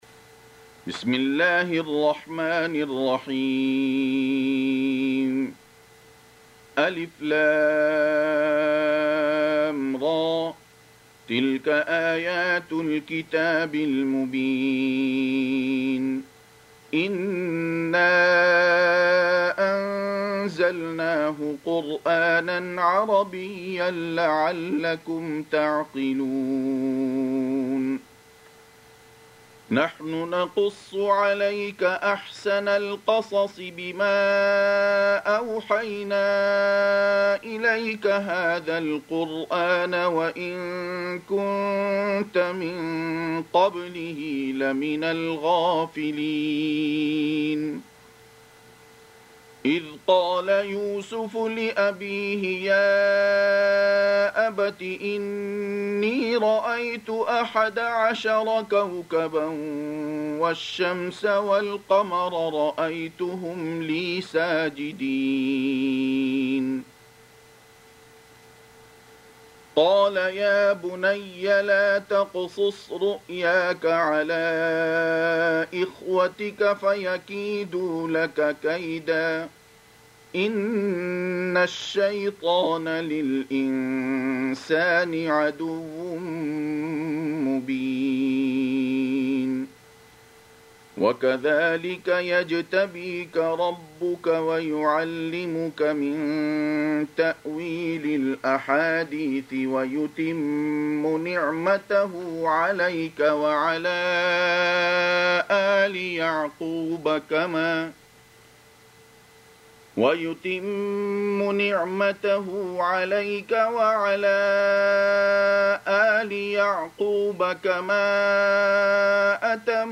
Surah Sequence تتابع السورة Download Surah حمّل السورة Reciting Murattalah Audio for 12. Surah Y�suf سورة يوسف N.B *Surah Includes Al-Basmalah Reciters Sequents تتابع التلاوات Reciters Repeats تكرار التلاوات